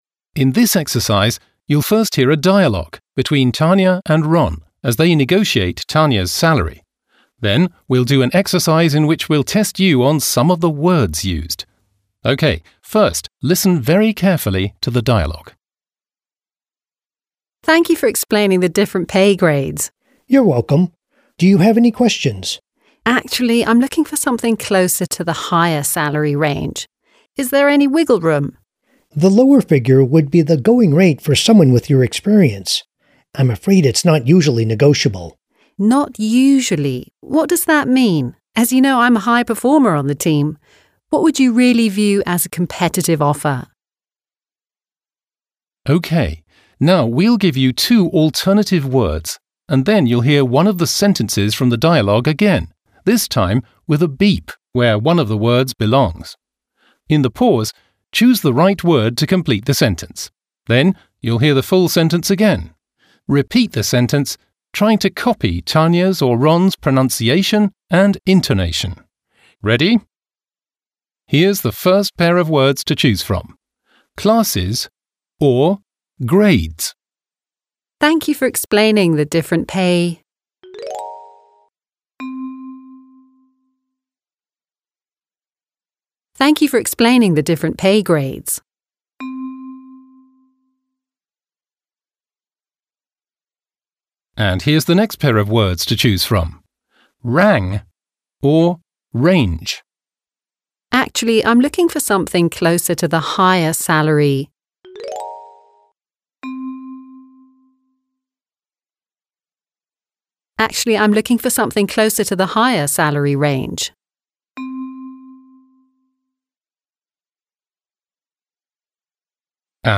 Audio-Trainer